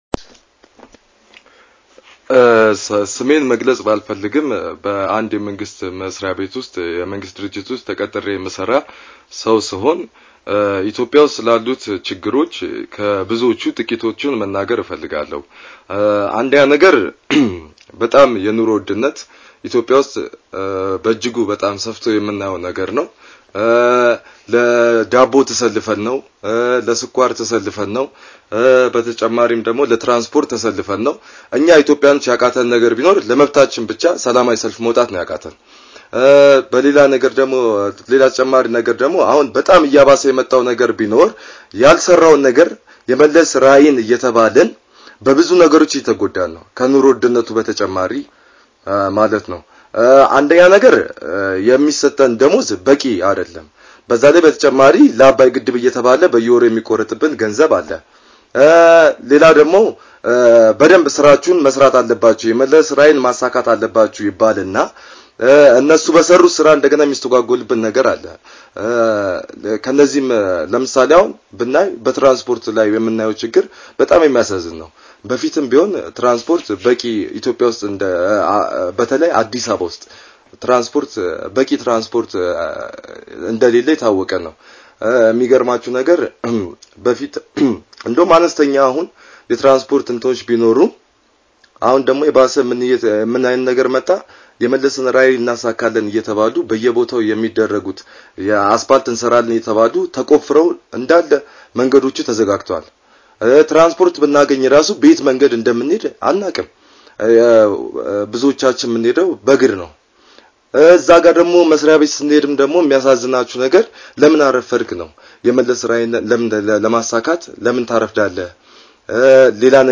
Haala kana ilaalchisuun Qeerroon jiraataa magalaa Finfinnee haasofsisee jira caqassa